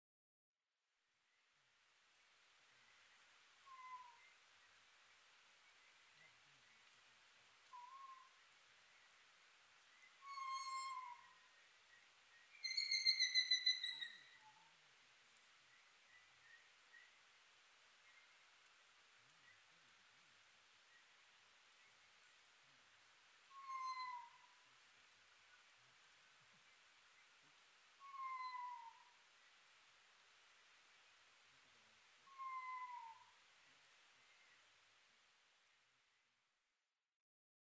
コノハズク　Otus scopsフクロウ科
日光市砥川上流　alt=550m
Mic: Panasonic WM-61A  Binaural Souce with Dummy Head
猫声＋キキキキキキキキ＋猫声